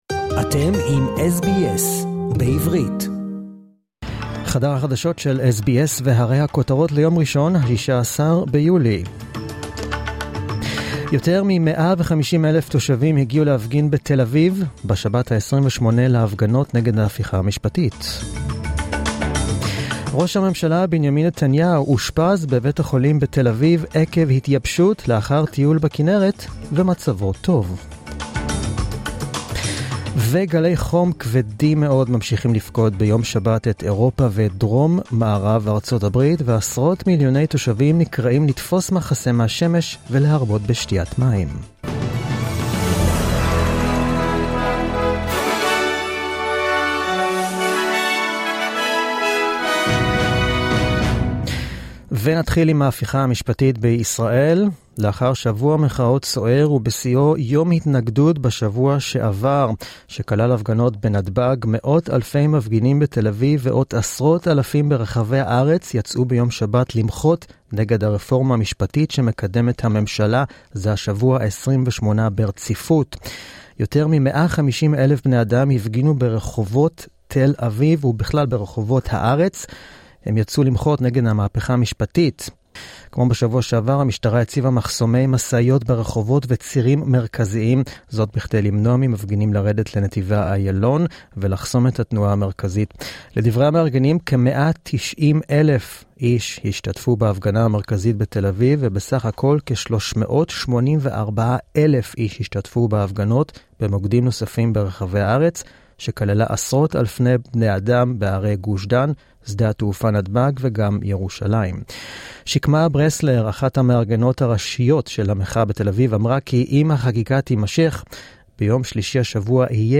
The latest news in Hebrew, as heard on the SBS Hebrew program on SBS Radio.